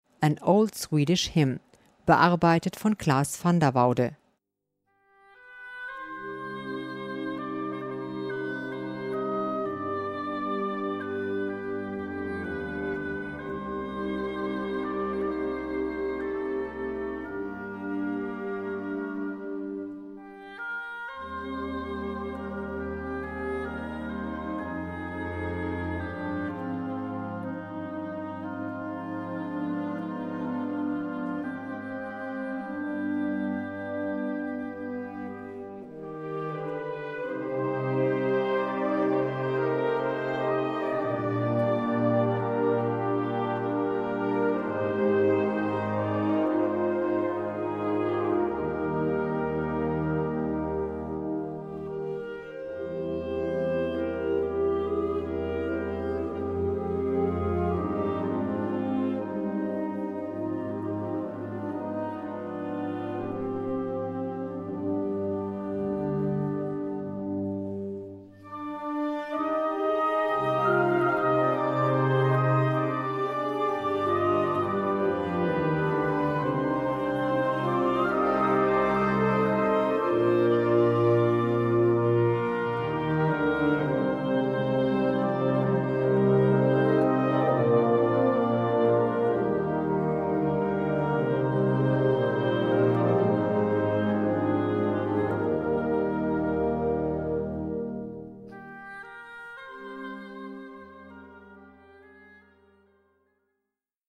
Besetzung: Blasorchester
im klassischen Stil gehalten